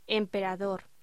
Locución: Emperador